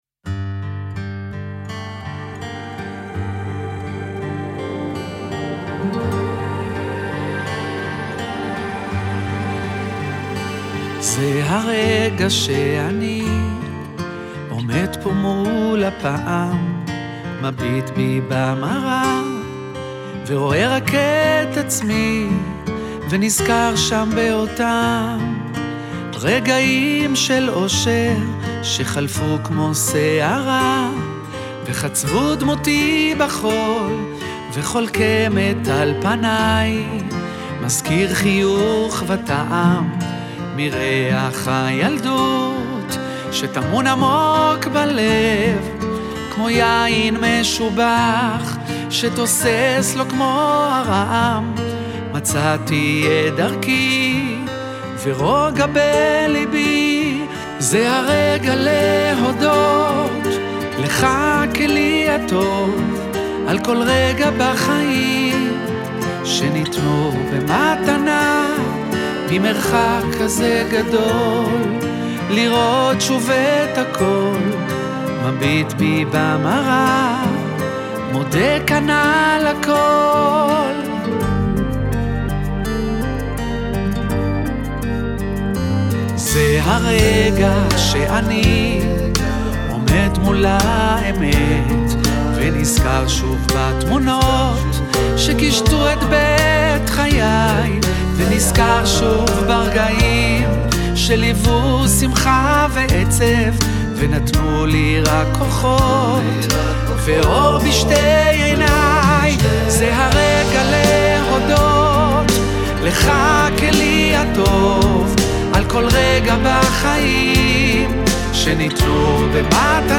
בלדה אישית נוגעת ללב על כל הטוב הזה
כלי נשיפה
גיטרה
כלי הקשה.